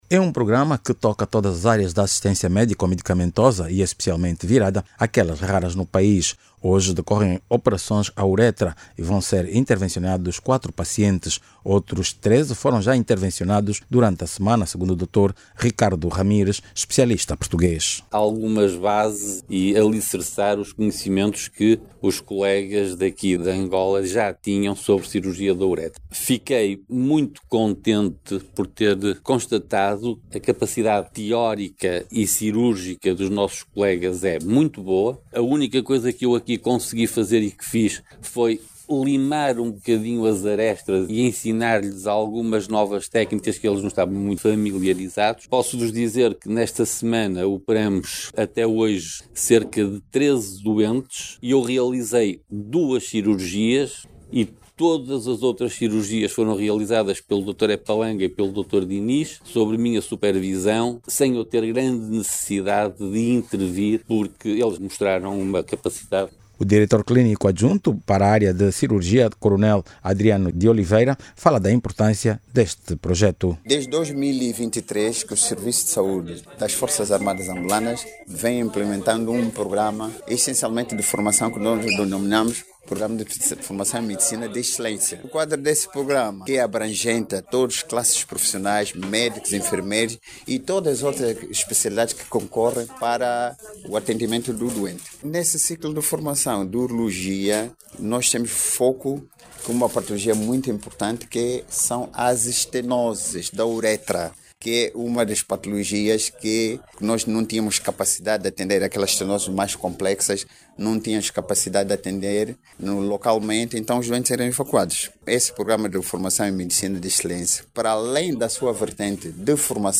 Hoje foram realizadas cirurgias complexas à uretra, um procedimento raro no país. Jornalista